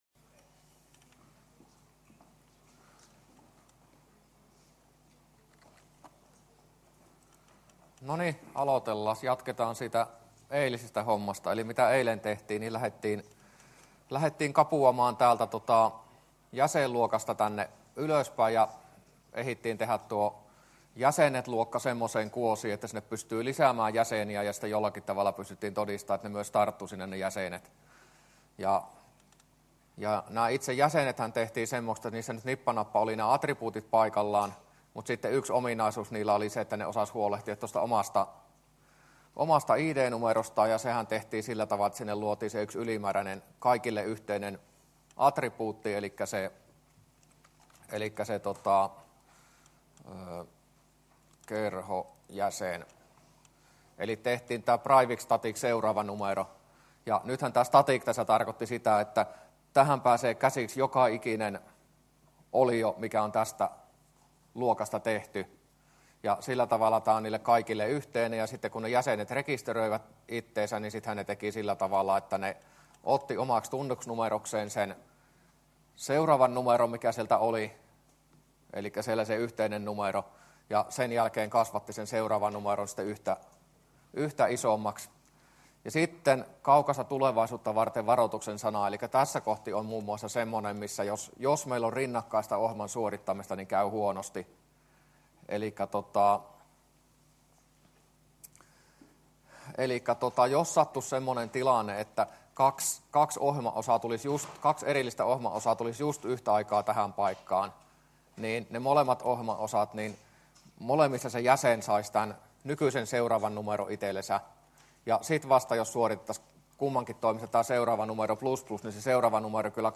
luento14a